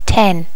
Additional sounds, some clean up but still need to do click removal on the majority.